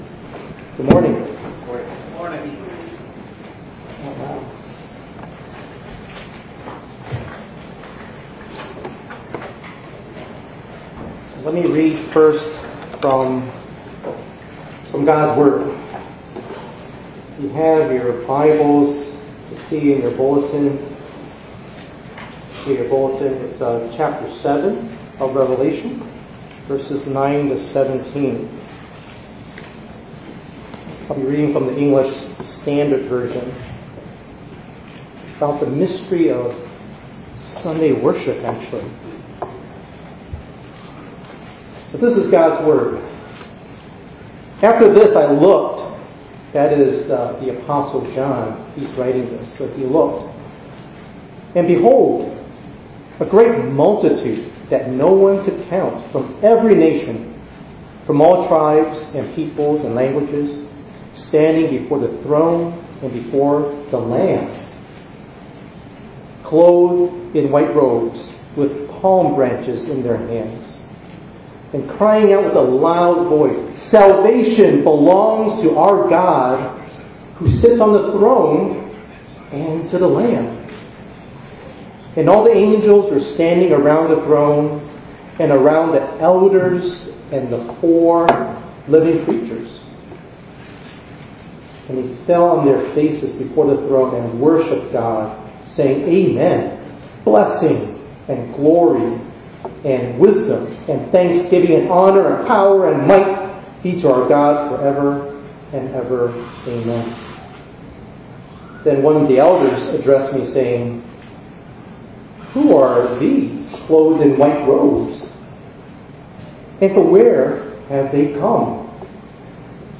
5_4_25_ENG_Sermon.mp3